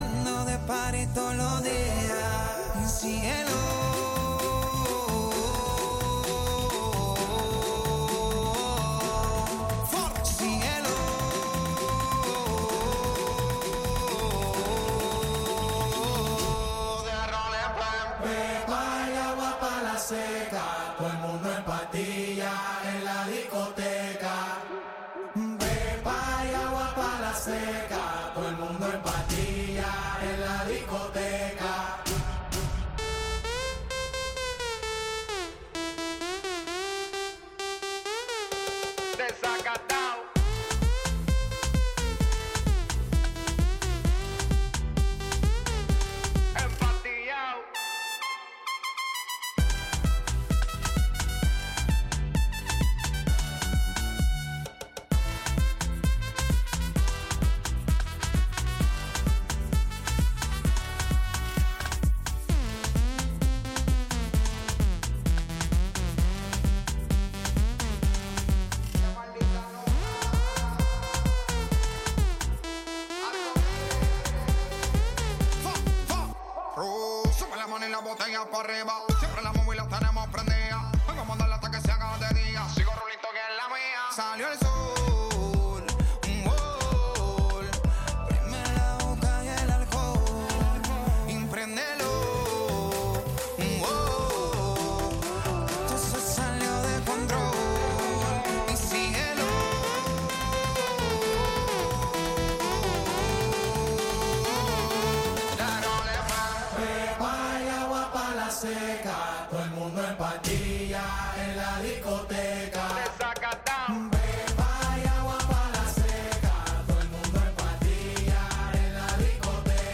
Some darker, abrasive and ambient music
some more mainstream Latin America music at the beginning